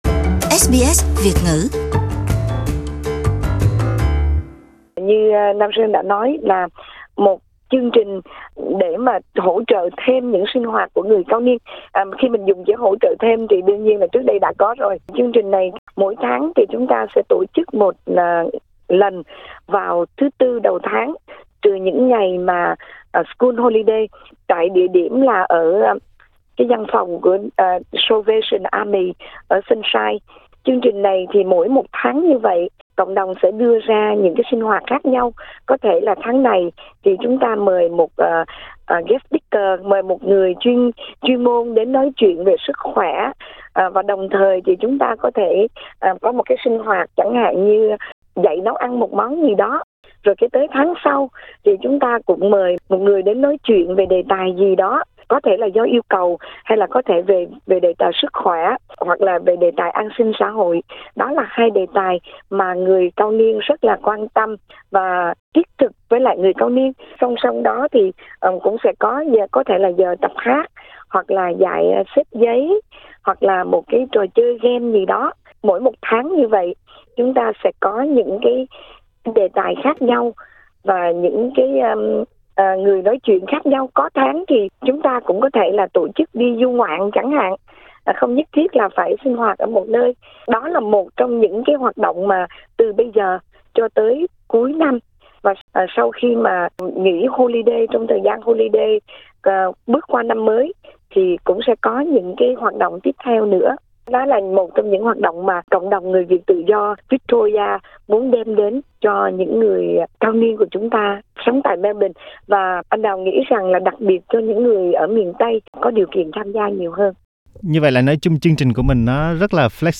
Vui lòng bấm vào phần audio để nghe toàn văn cuộc phỏng vấn.